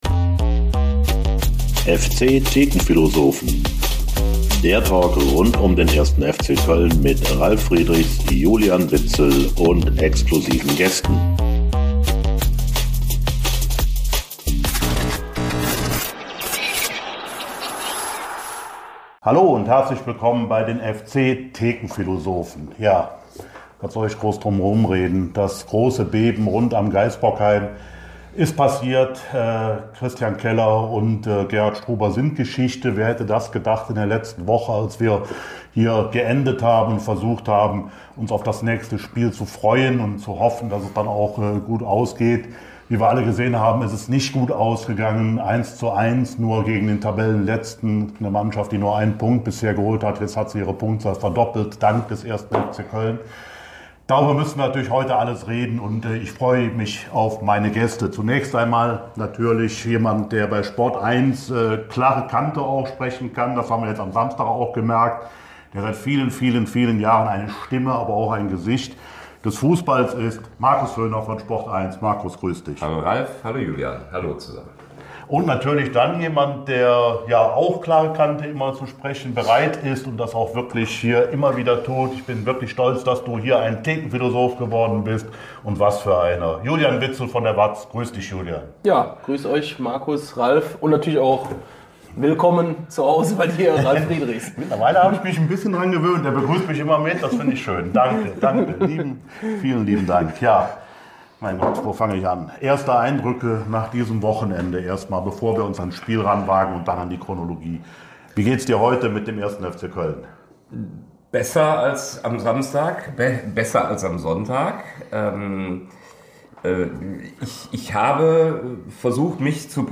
Der Talk Podcast